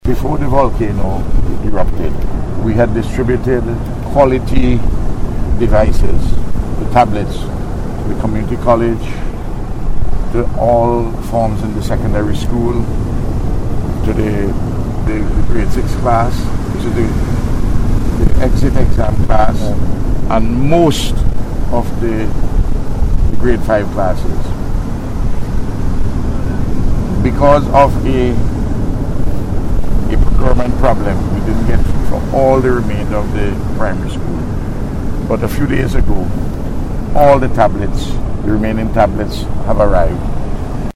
He made this statement during a tour to the windward side of the country in the designated Red Zone yesterday with a team from the United Nations to access the damage.